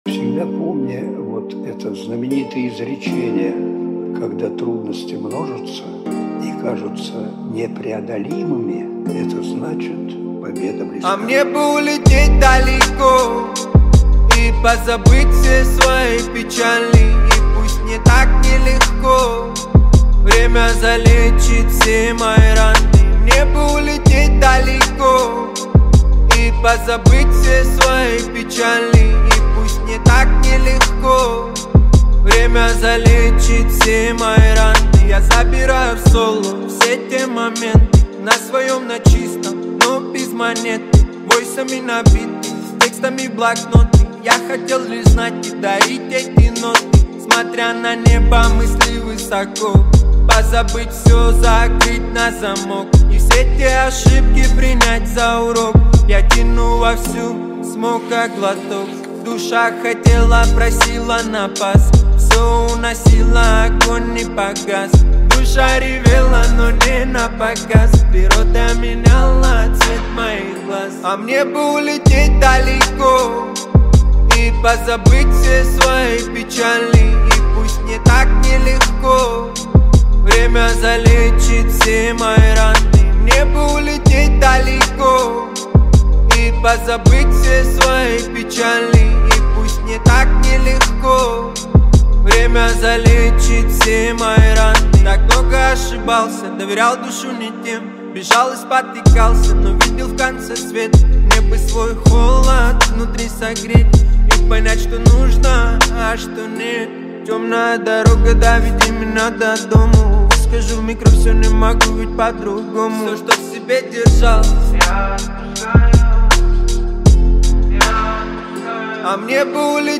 Русские песни
• Качество: 320 kbps, Stereo